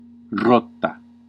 Ääntäminen
US : IPA : [ʍiːl]